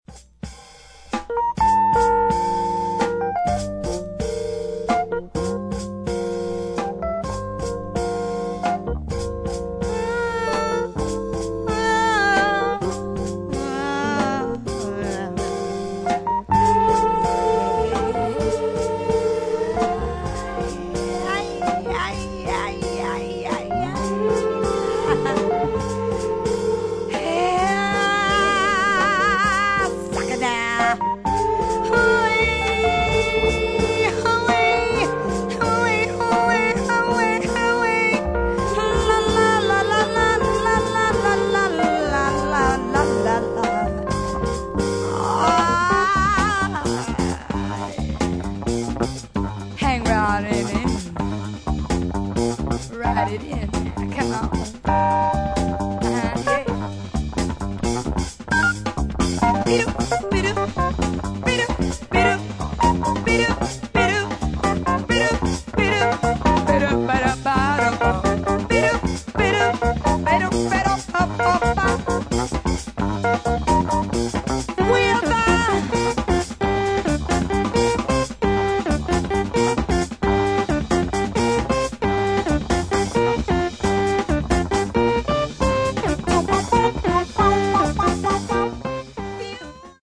グルーヴィーなリズム隊と鍵盤の絡みが気持ちよく、ノリの良さにディープさも兼ね備えたジャズ・ファンク